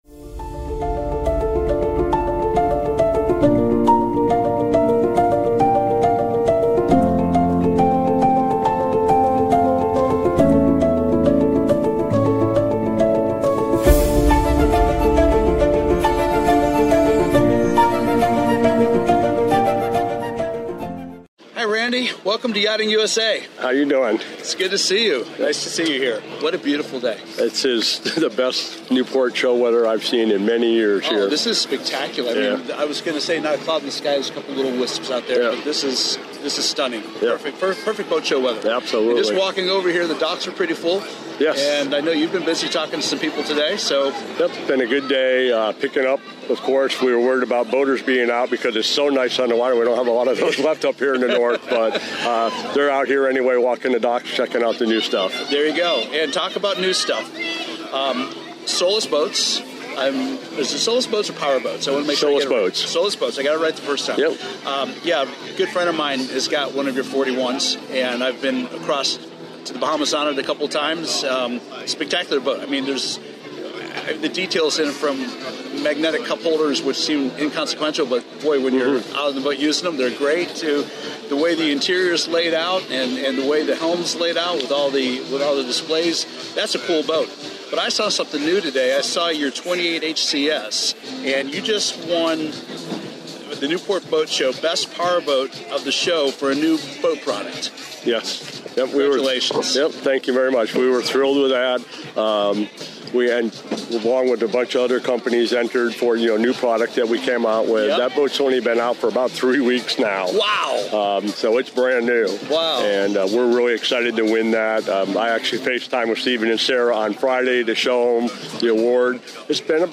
🚤 In this interview